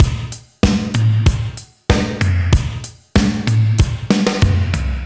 Index of /musicradar/80s-heat-samples/95bpm
AM_GateDrums_95-01.wav